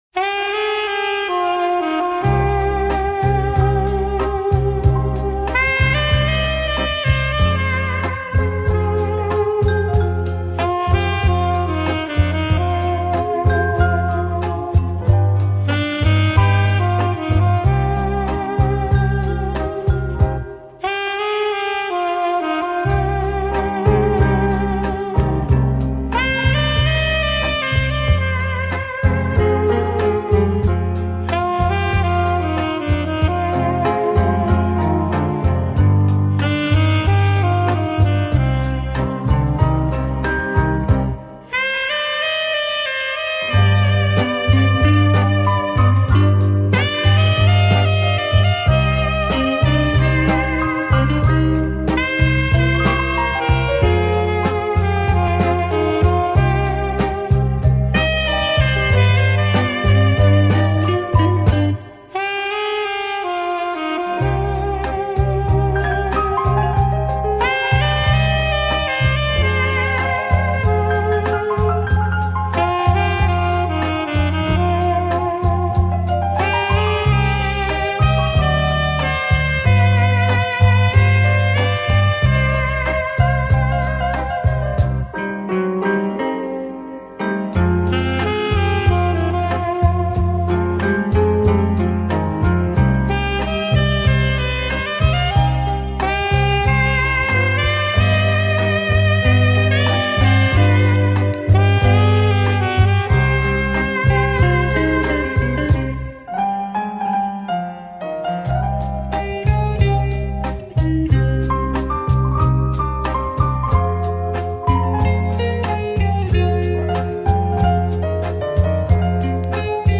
По манере исполнения похоже на японцев.
У меня он записан пока как "Инструментальный ансамбль - Саксофон (Япония)"